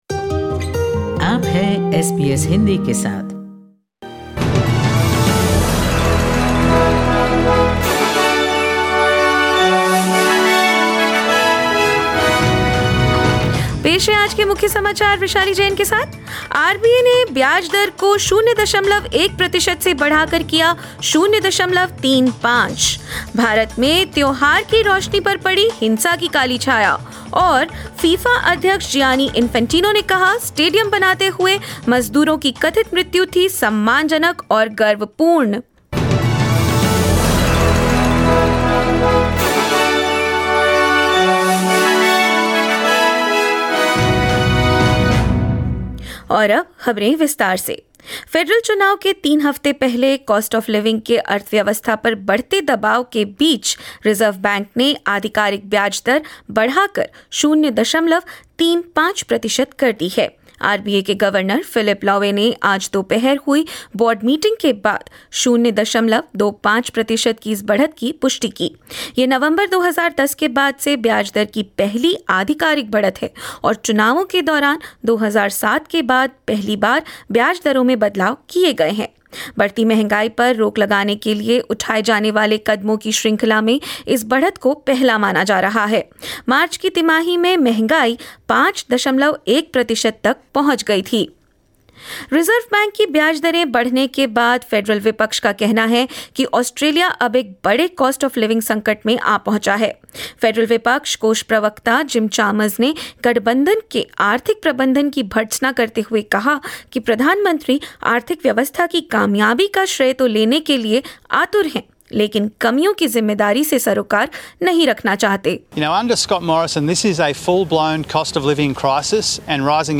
In this SBS Hindi bulletin: Reserve Bank of Australia raises the official interest rate from 0.1 percent to 0.35 percent in a bid to control inflation; Parts of India see violence and curfew on the occasion of Eid and Akshay Tritiya; FIFA President Gianni Infantino says migrant workers who reportedly died while preparing the World Cup Stadium did so with, "dignity and pride" and more.